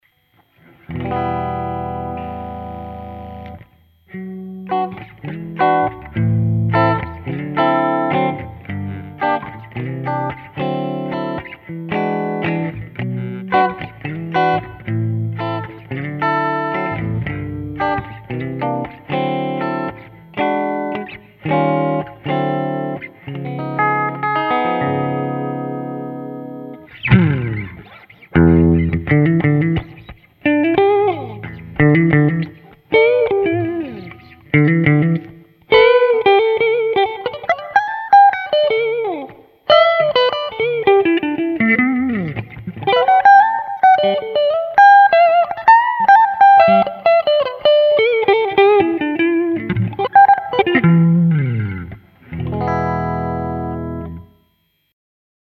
La première partie du sample, que tu joues en finesse, fait illusion (même si je la trouve un peu trop brillante mais il faut voir ton matériel), par contre la seconde partie dès que tu attaques les cordes alors là pour moi il y a un problème. Le son s'écrase devient mat et franchement je ne reconnais pas un Hiwatt là dedans ni même un ampli.
Tout à fait d'accord, je dirais même qu'il a mis un compresseur.